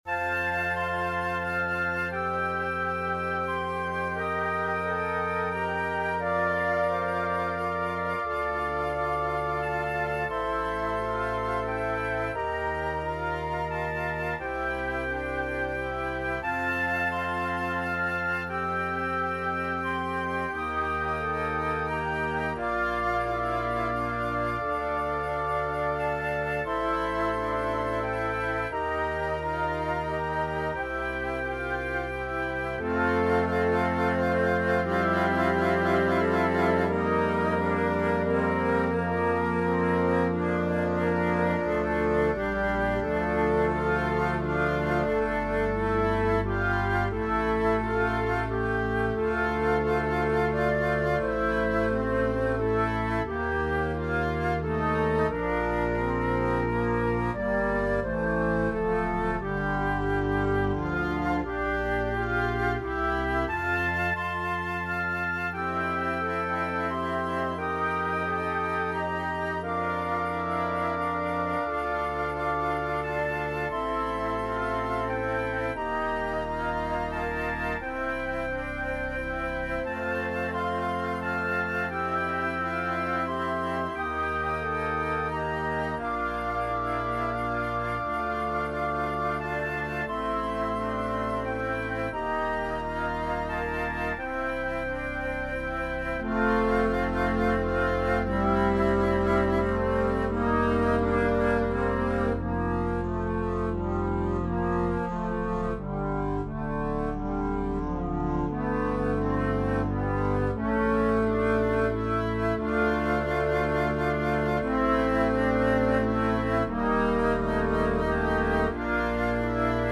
Arranged for organ solo
Voicing/Instrumentation: Organ/Organ Accompaniment